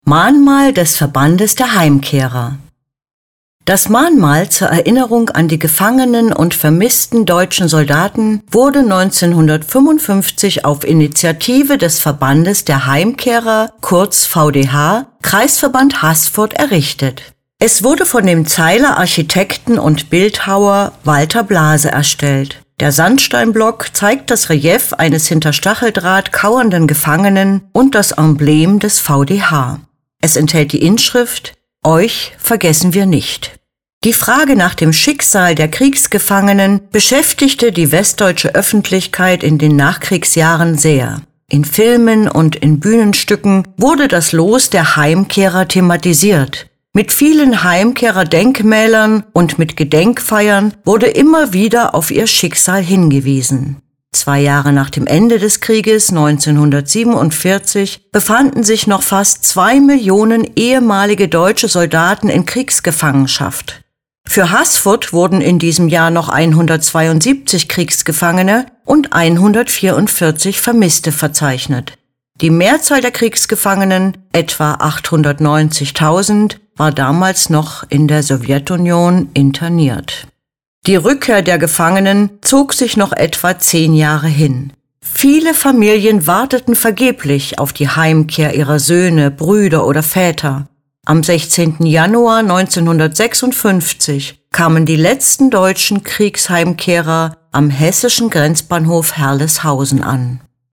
Lassen Sie sich die Infos zu diesem Werk einfach vorlesen.